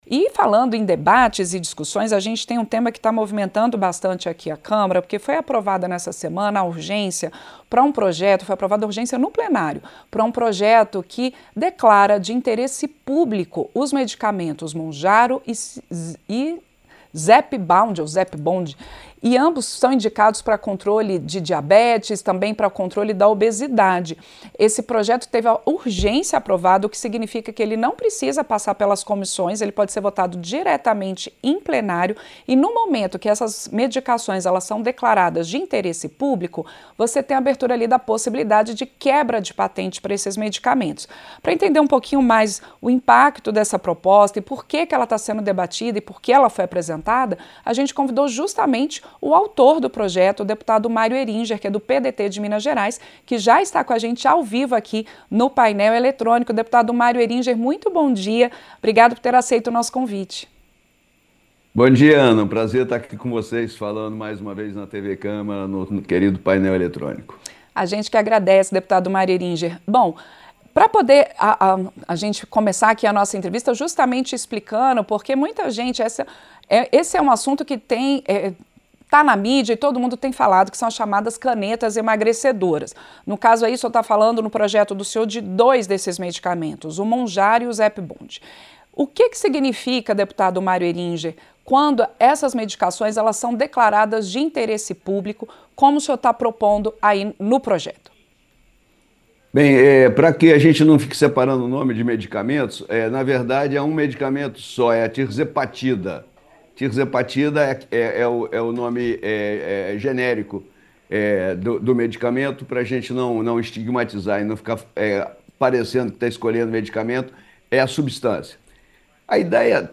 Entrevista - Dep. Mário Heringer (PDT-MG)